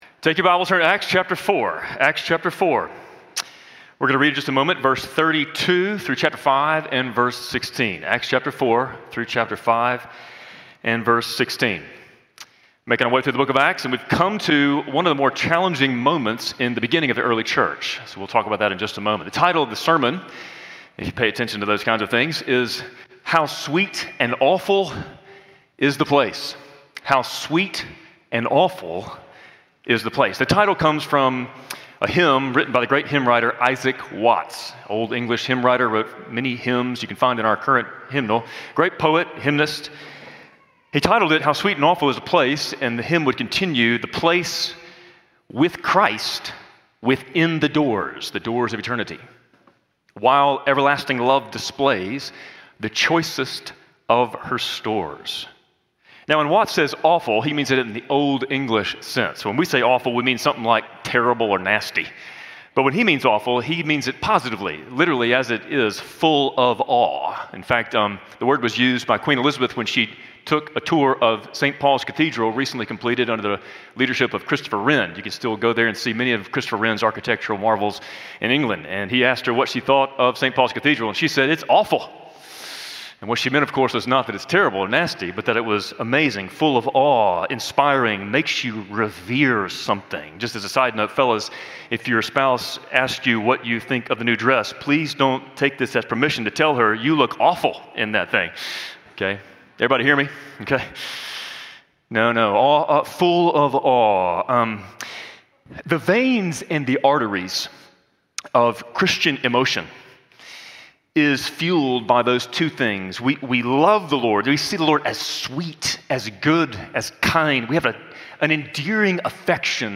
Spring Hill Baptist Sunday Sermons (Audio) / How Sweet and Awful Is the Place